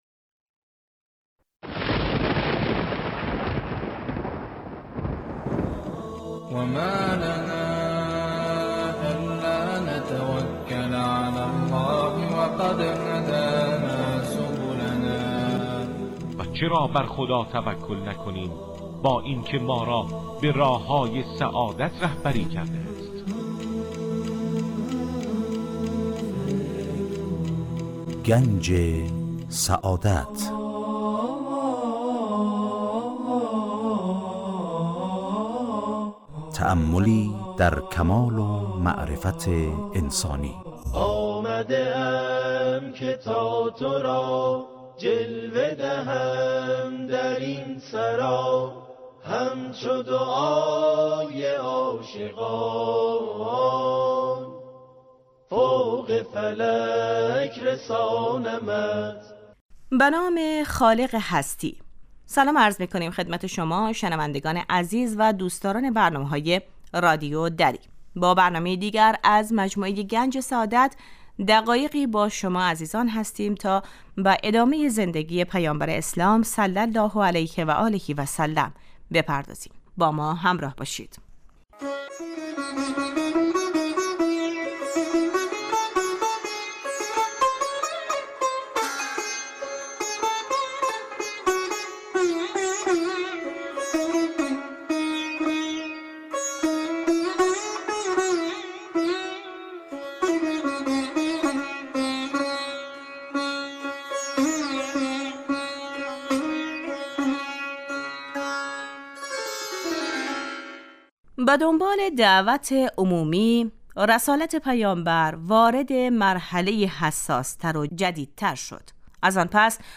گوینده : سرکار خانم